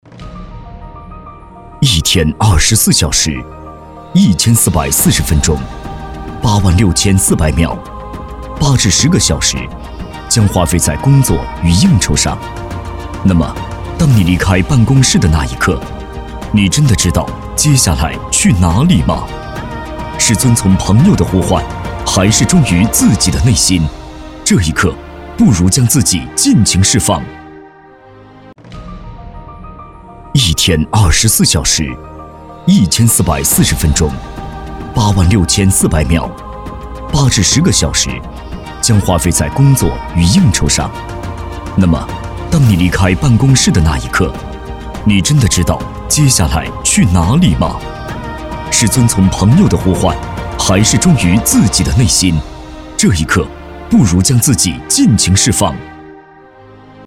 职业配音员全职配音员浑厚大气
• 男S353 国语 男声 公益广告-太保梦-时间公益广告-抒情有力 大气浑厚磁性|科技感|积极向上